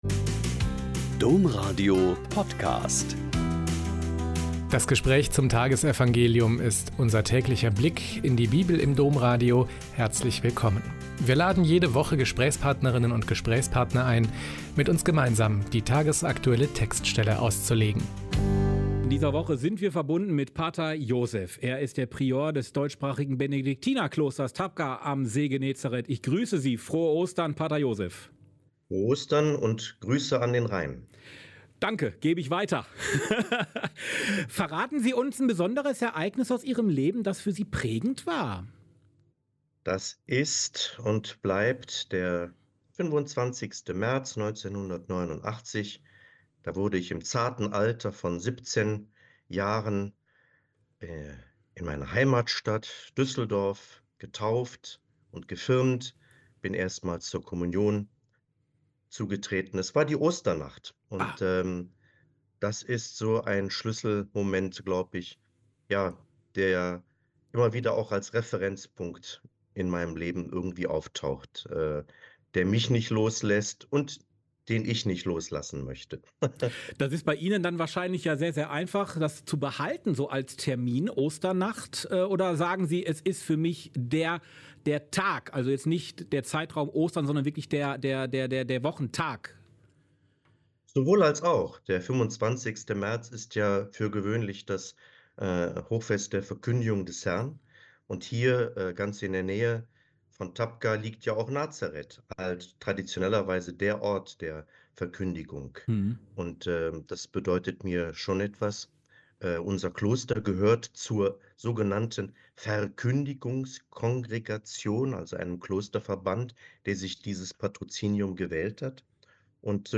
Joh 20,11-18 - Gespräch